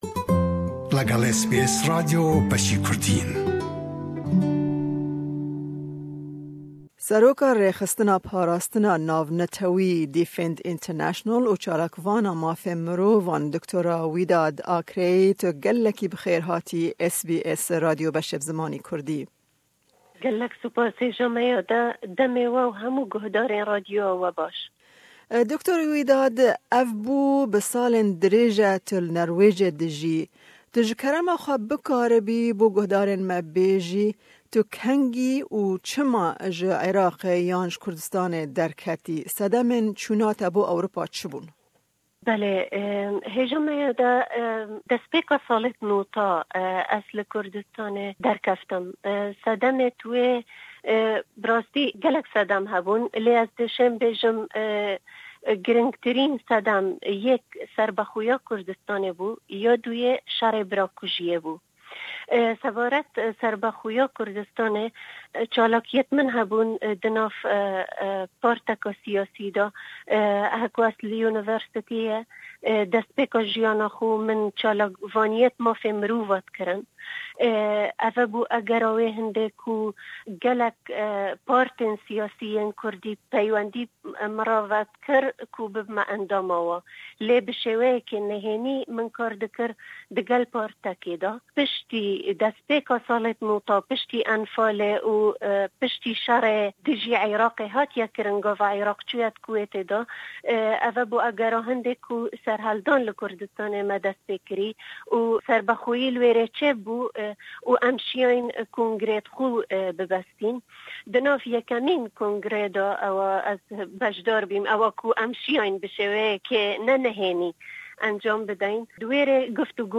We spoke to her about winning the peace and freedom award, reasons she left Kurdistan in early 1990s, her thoughts about the passing of a prominent Kurdish leader Jalal Talabani and Kurdistans independence referendum.